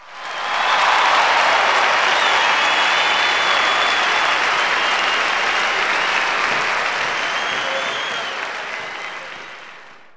clap_048.wav